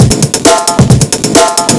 Index of /breakcore is not a good way to get laid/155BPM/silentkillerbreaks